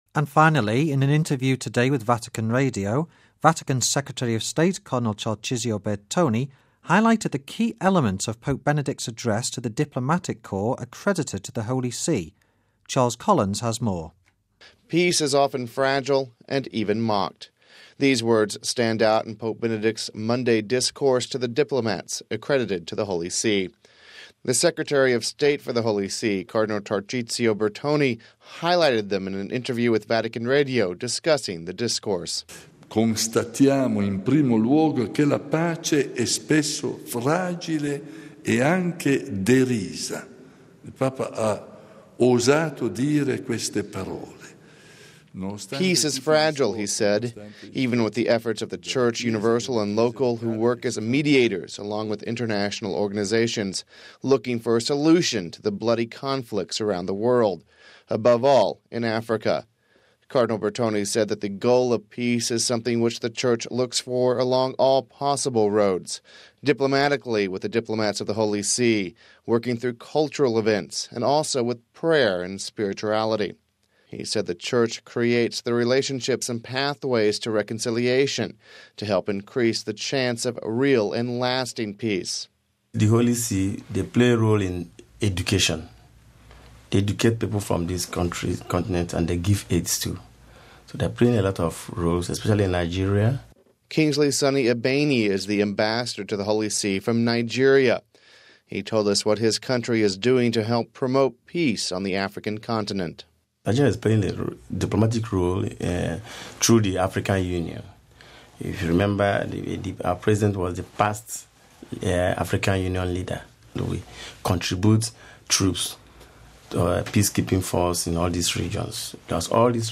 (9 Jan '07 - RV) In an interview today with Vatican Radio, Vatican Secretary of State Cardinal Tarcisio Bertone highlighted the key elements of Pope Benedict’s address to the diplomatic corps accredited to the Holy See.